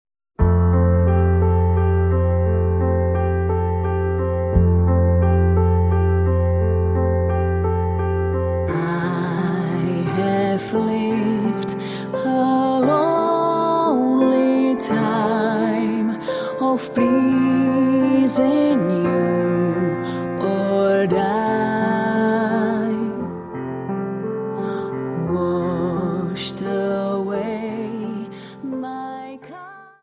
Dance: Slow Waltz 29